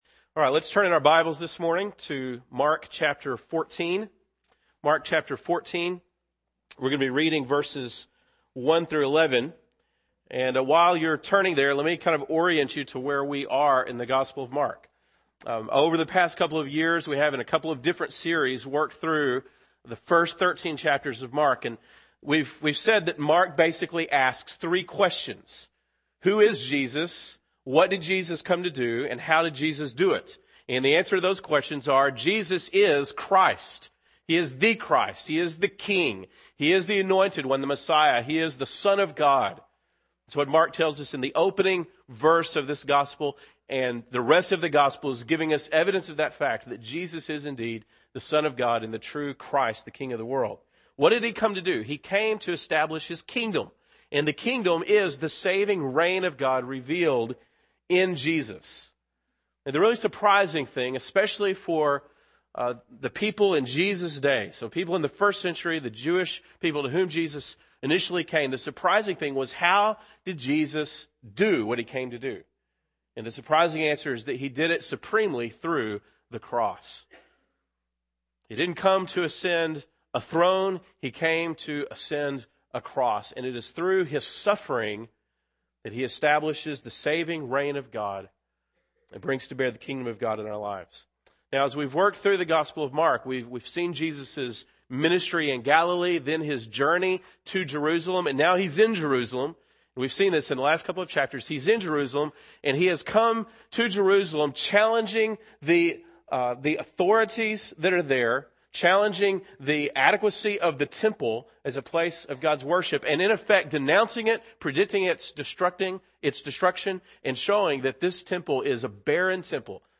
February 22, 2009 (Sunday Morning)